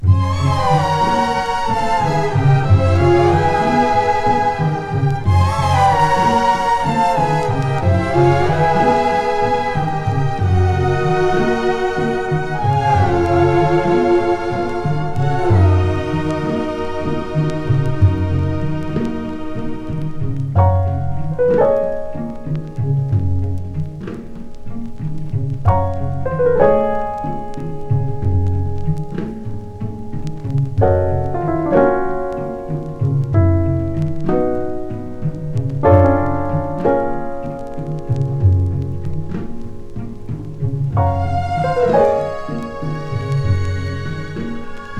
選曲も良く、静けさと贅沢なムードに包まれる1枚です。
Jazz, Easy Listening, Lounge　USA　12inchレコード　33rpm　Stereo